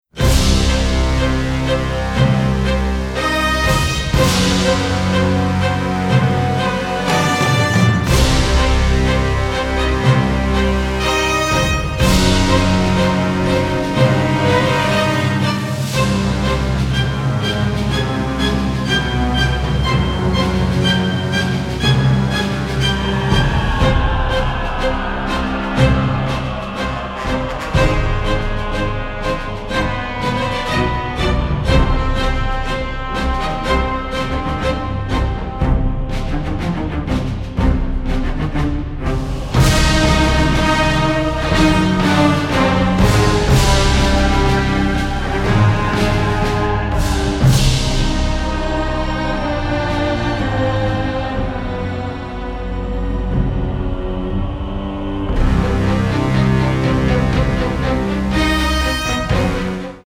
dynamic score, written in a symphonic jazz style
Recorded in London